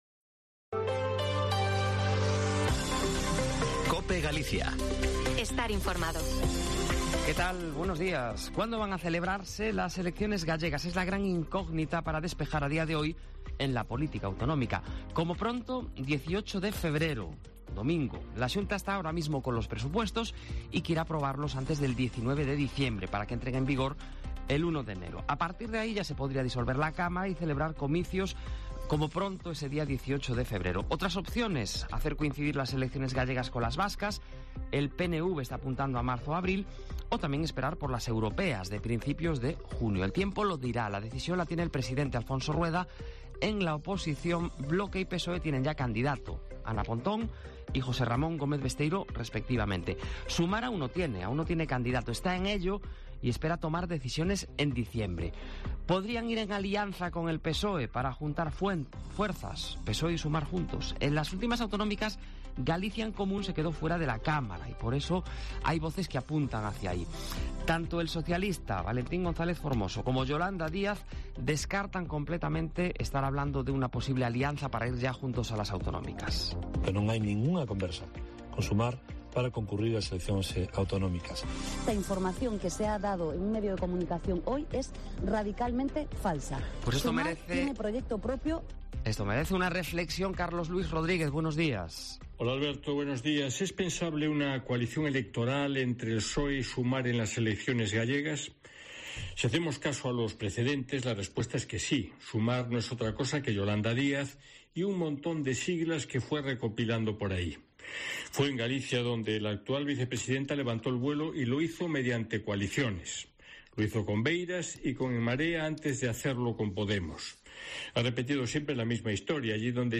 OPINIÓN
En su comentario de opinión de este jueves en Herrera en Cope Galicia